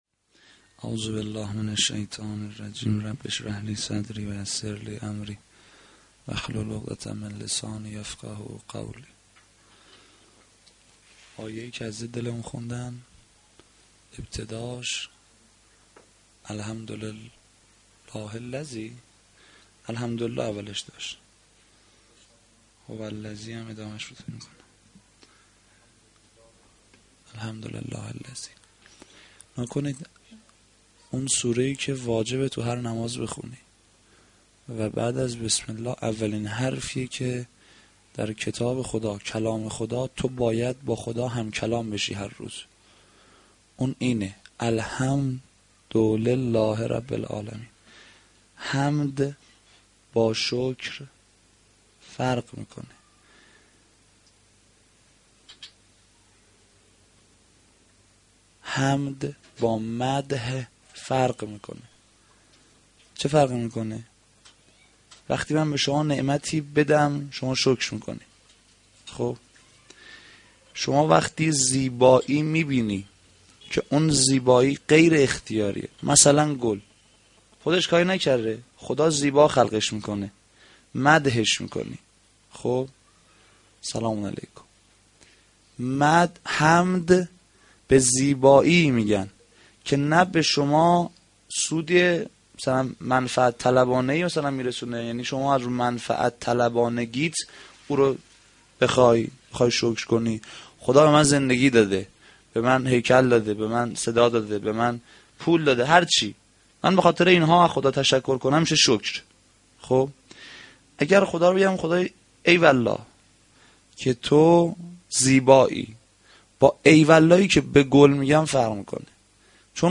sh-7-moharram-92-sokhanrani.mp3